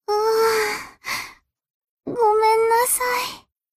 贡献 ） 分类:蔚蓝档案语音 协议:Copyright 您不可以覆盖此文件。
BA_V_Nonomi_Battle_Retire.ogg